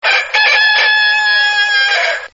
» Kukareku Размер: 12 кб